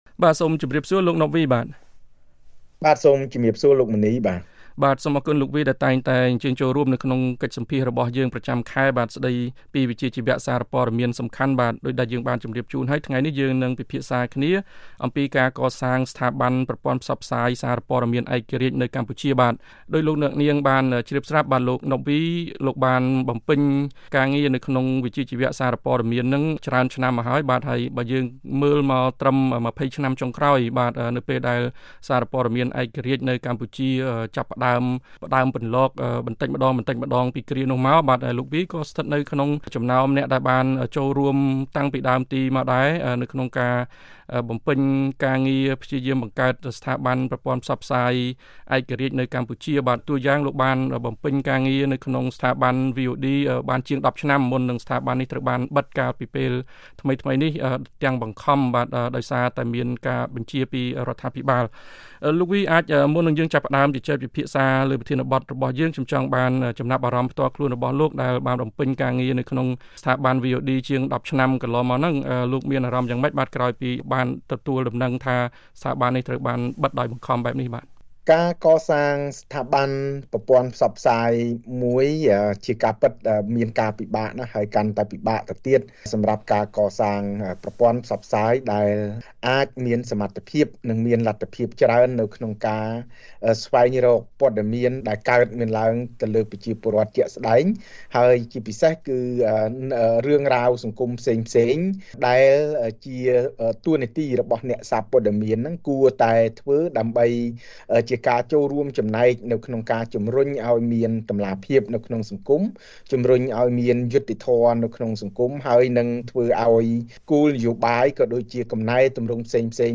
បទសម្ភាសន៍ VOA៖ ស្ថាប័នសារព័ត៌មានឯករាជ្យ រឹតតែចាំបាច់ក្នុងប្រទេសដែលអំណាចទាំង៣មិនអាចបែងចែកដាច់ពីគ្នា